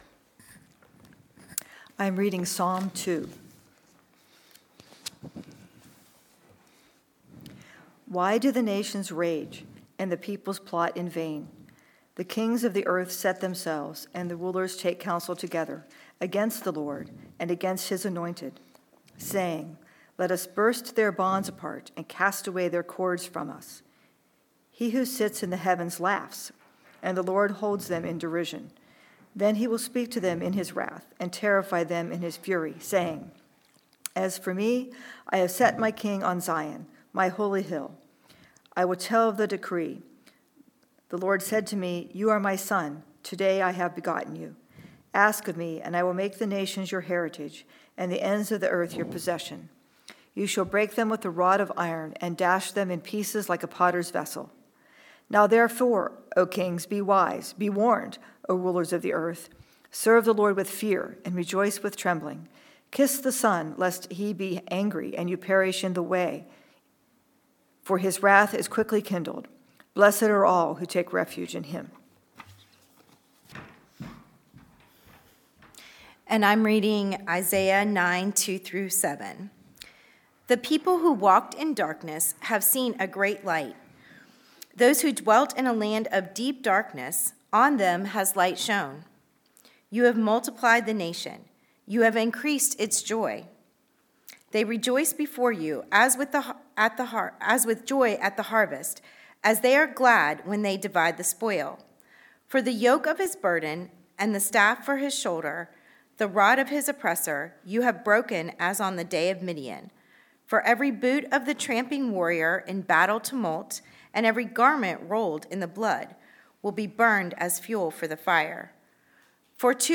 We hunger for Jesus to come again and bring the Kingdom of God into its fullness. Message from John 18:33-40. First Sunday of Advent.